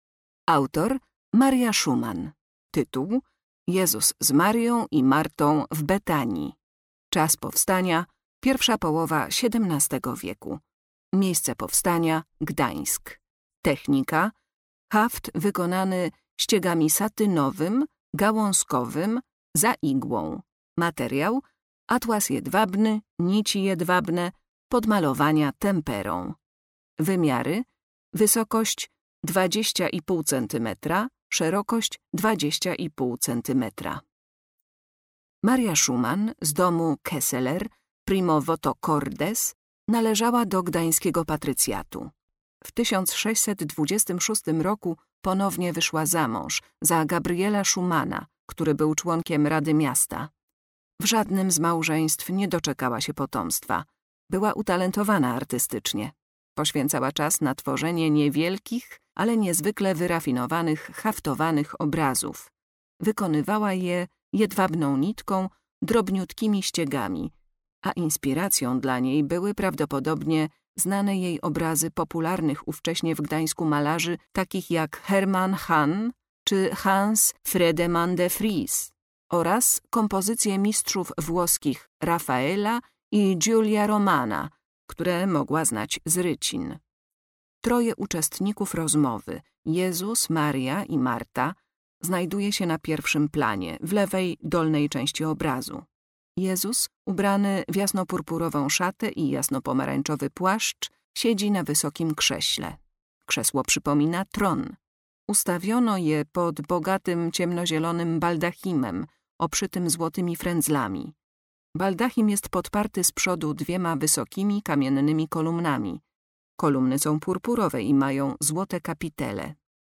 Audiodeskrypcje do wystawy stałej w Oddziale Sztuki Dawnej